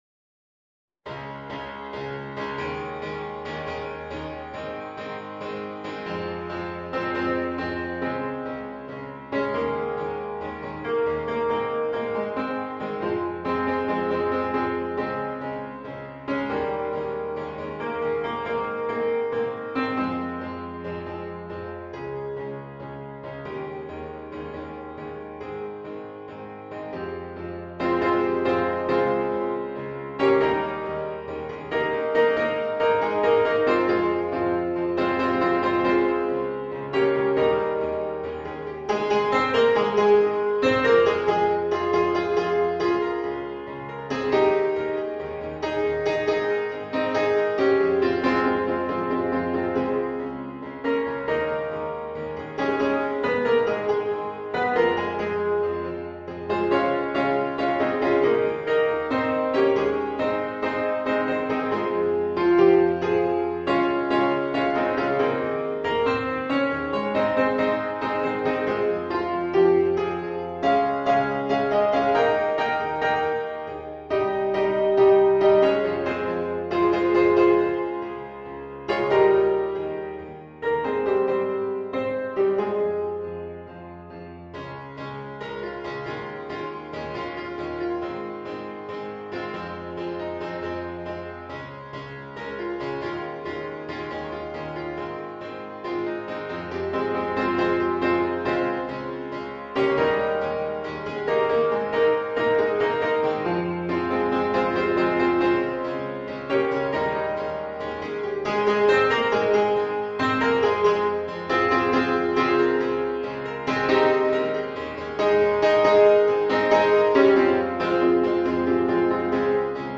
Piano / Vocal Only
SATB
Transition